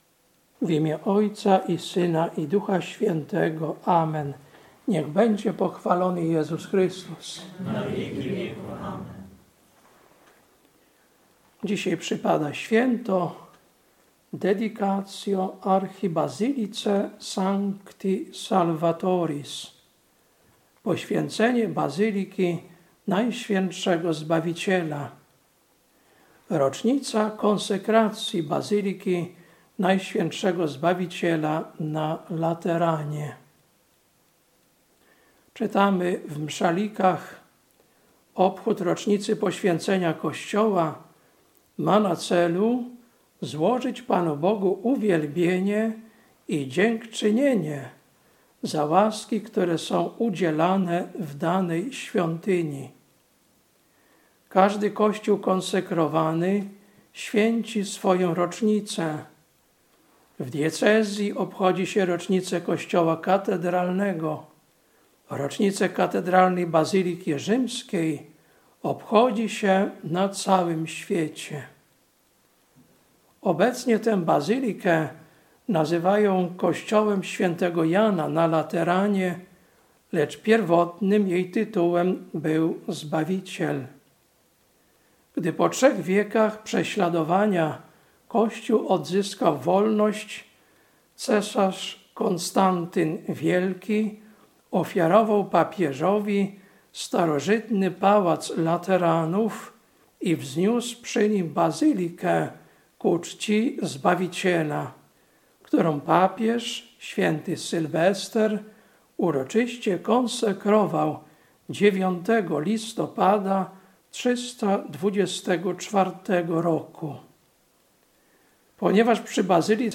Kazanie na Rocznicę Konsekracji Bazyliki Najświętszego Zbawiciela na Lateranie, 9.11.2025